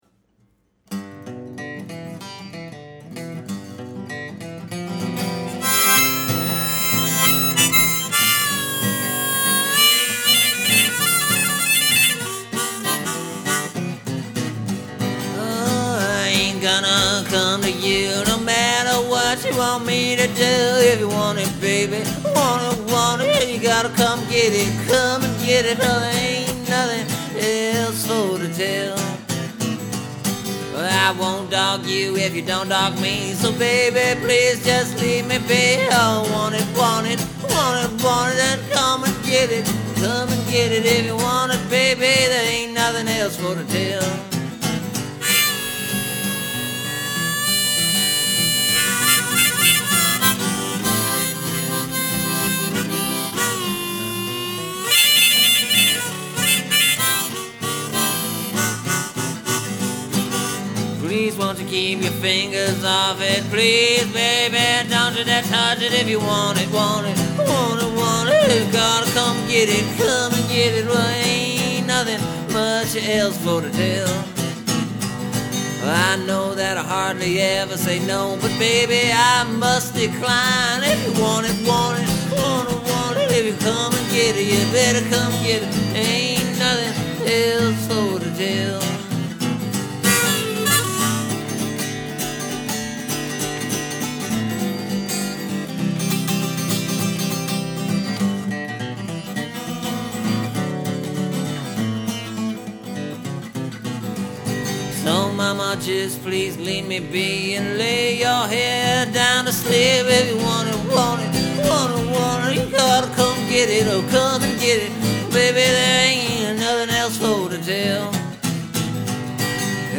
So, just like the original version (which you can listen to here for comparison), for this new recording I used an old melody.
It’s easier and flows a little better.